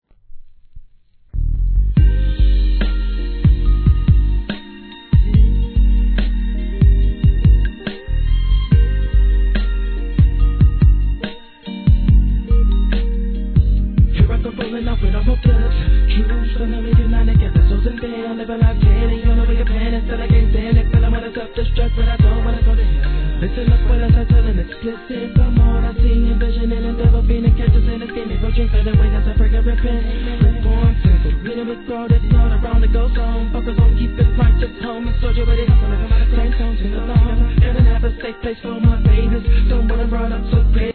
G-RAP/WEST COAST/SOUTH
ネタ感あるソウルなメロ〜なトラック上を得意の高速RAP!! サビのコーラスも最高！